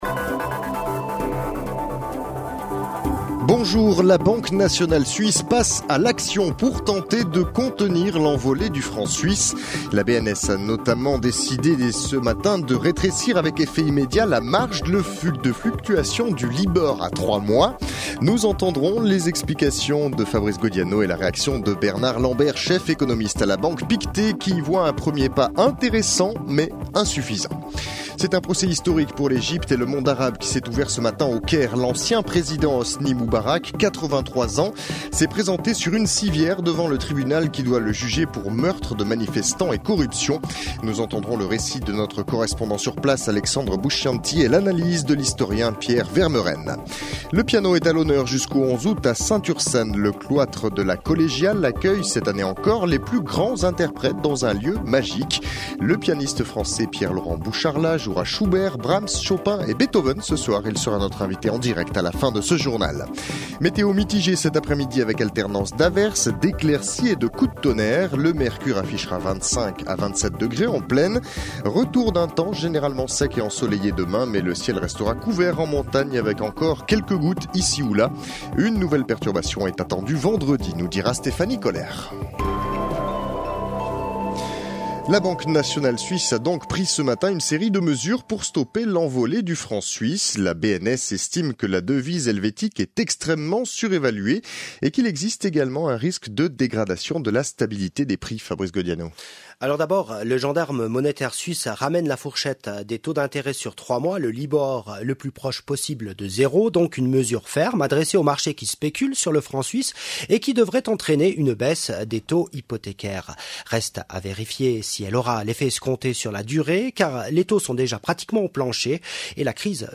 Le 12h30, c’est le grand rendez-vous d’information de la mi-journée. L’actualité dominante y est traitée, en privilégiant la forme du reportage/témoignage pour illustrer les sujets forts du moment.